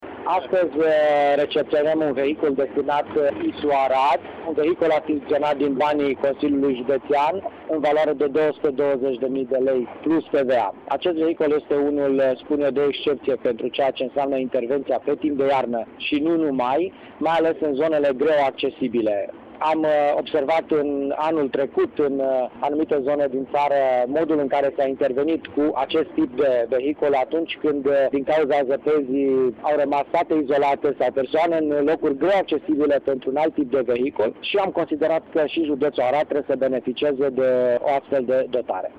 Inspectoratul pentru Situații de Urgență Arad a primit în dotare o autoșenilată amfibie. Utilajul a fost cumpărat din fondurile Consiliului Județean Arad, spune președinte CJA, Nicolae Ioțcu.